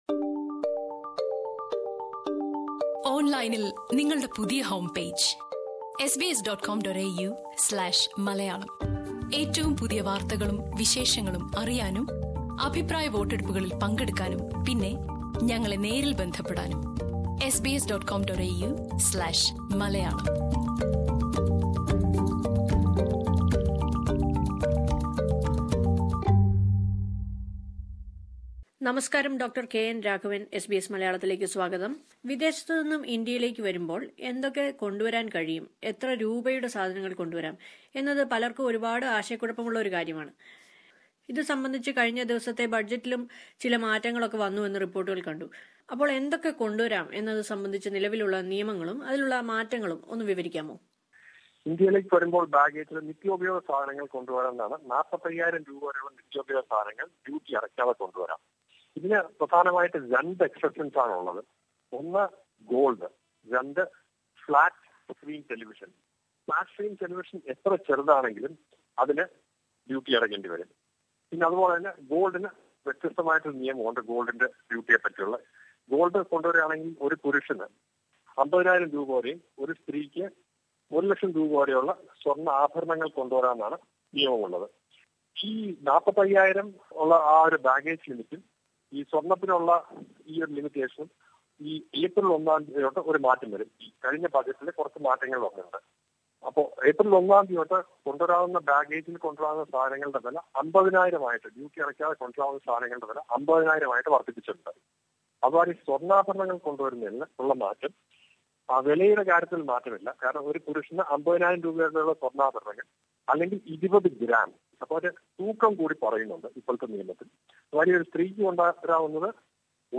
കൊച്ചിയിലെ കസ്റ്റംസ് കമ്മീഷണറായ ഡോക്ടർ കെ എൻ രാഘവൻ അതേക്കുറിച്ച് വിശദീകരിക്കുന്നത് മുകളിലെ പ്ലേയറിൽ നിന്ന് കേൾക്കാം. After listening to the interview, scroll down for more details.